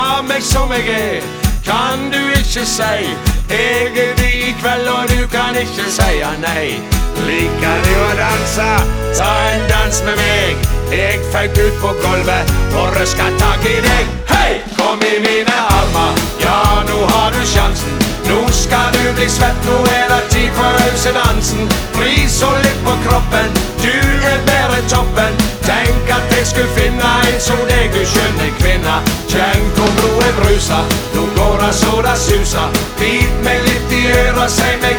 # Comedy